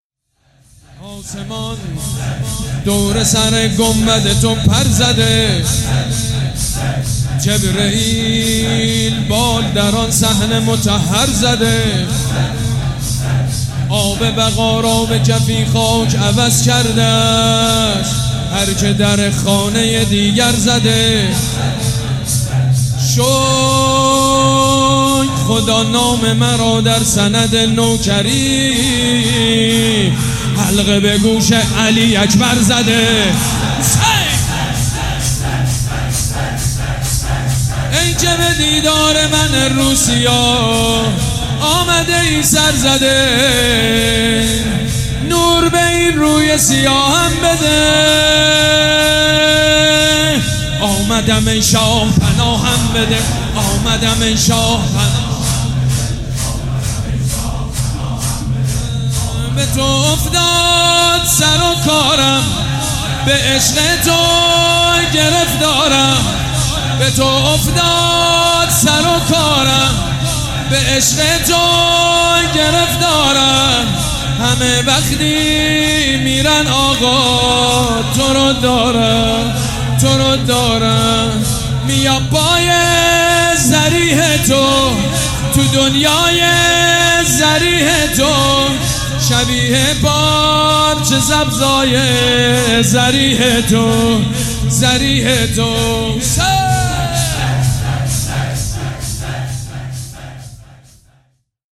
مداحی جدید حاج سید مجید بنی فاطمه شب اول محرم98 هیات ریحانة الحسین تهران شنبه 09 شهریور 1398
شور